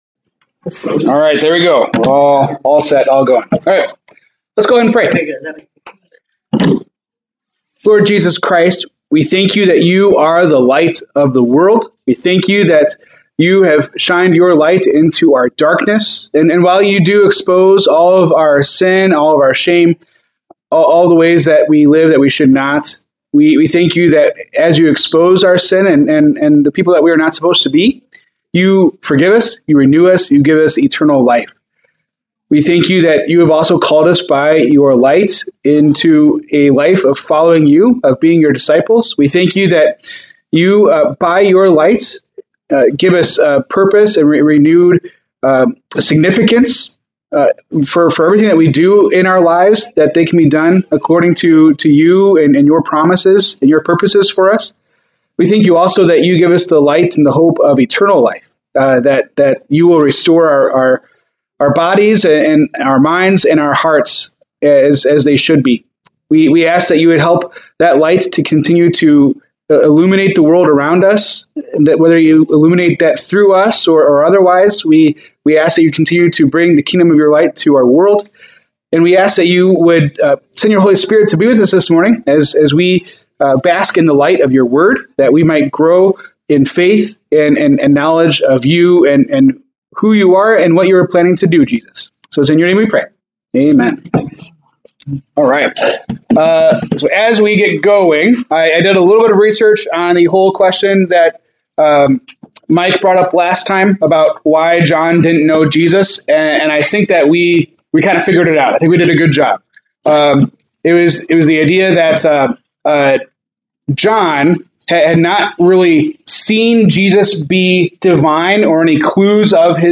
January 25, 2026 Bible Study